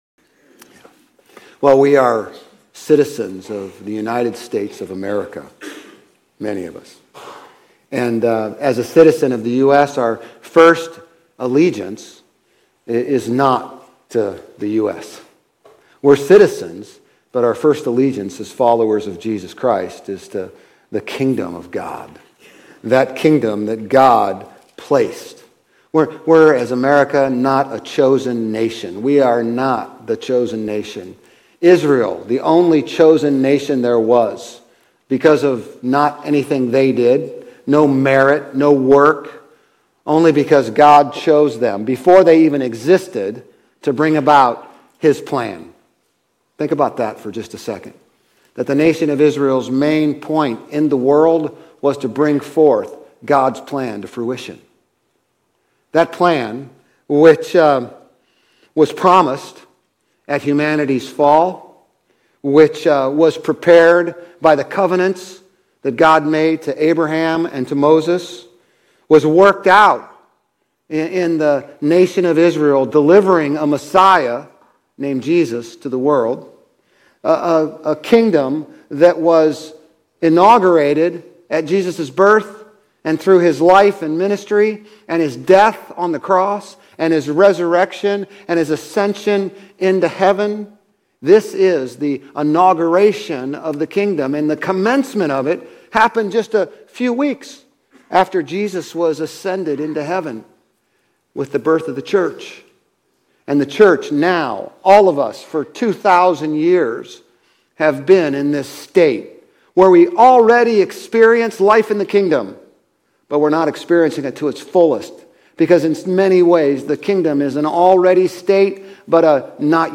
Grace Community Church Old Jacksonville Campus Sermons 1_26 Sermon on the Mount Jan 27 2025 | 00:30:11 1x 00:00 / 00:30:11 Subscribe Share RSS Feed Share Link Embed